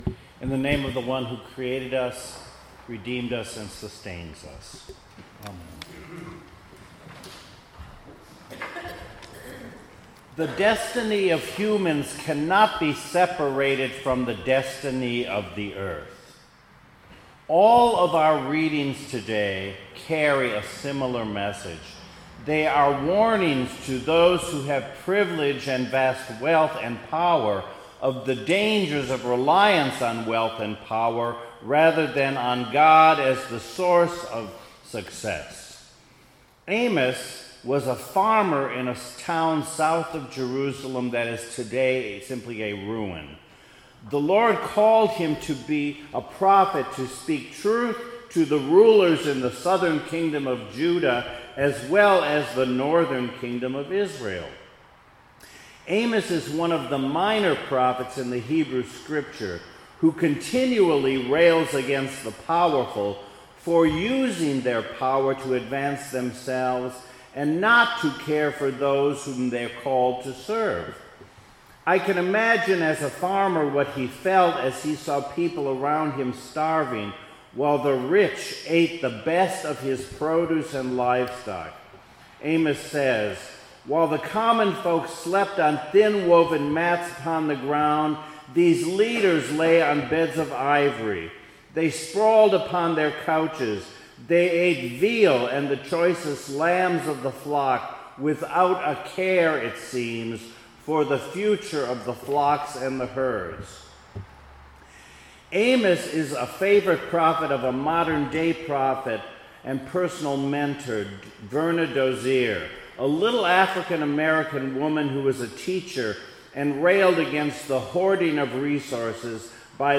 2019 Sunday Sermon